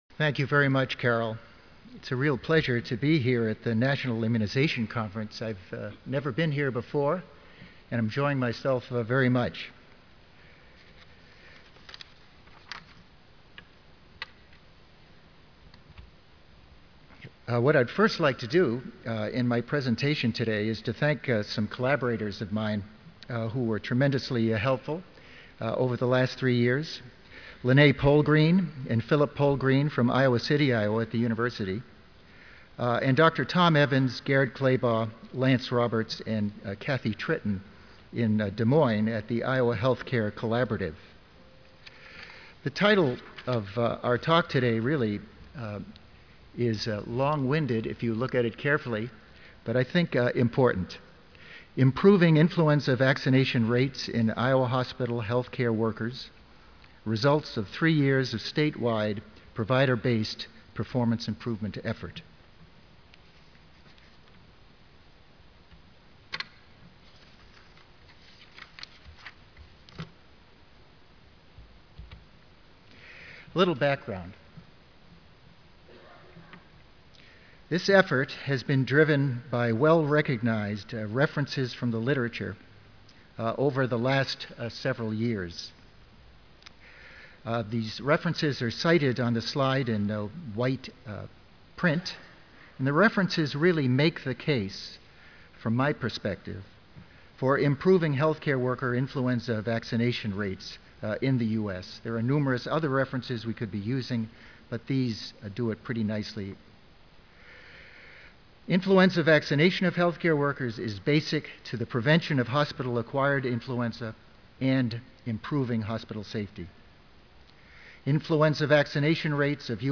44th National Immunization Conference (NIC): FEATURED SESSION: Influenza Vaccination among Healthcare Workers
Recorded presentation